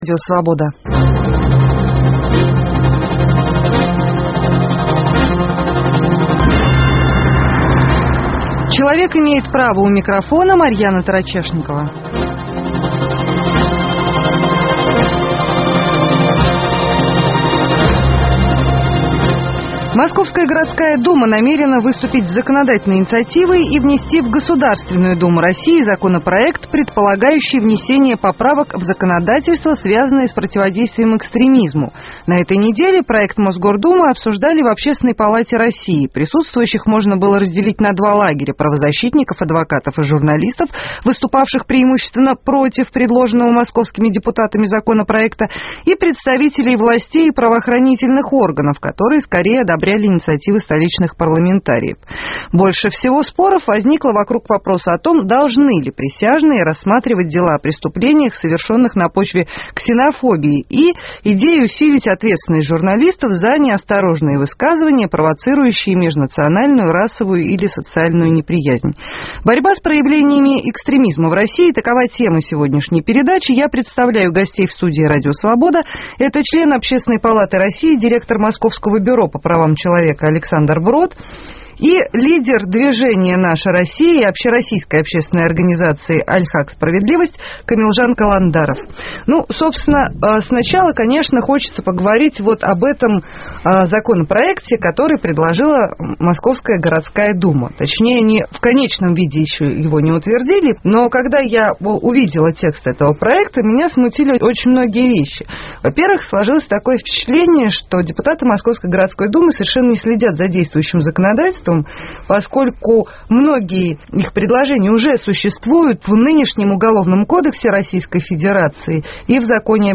В студии РС